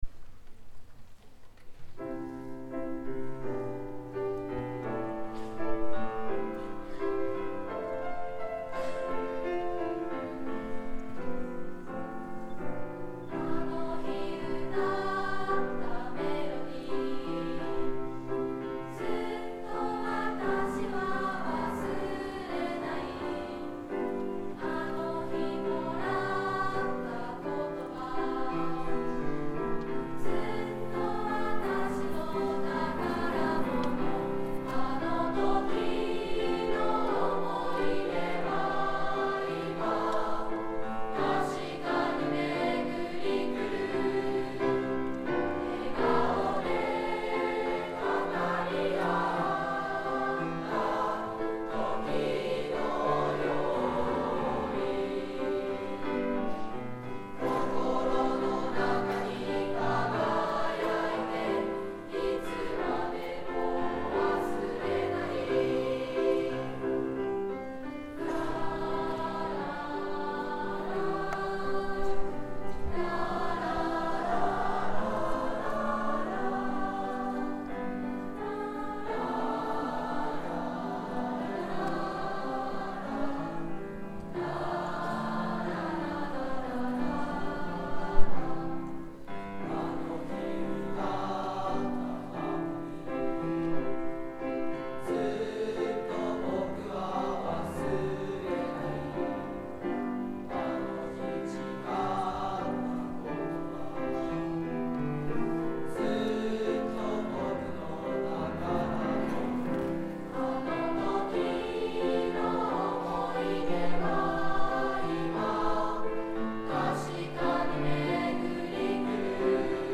２Ｂ 心の中にきらめいて.mp3 ←クリックすると合唱が聴けます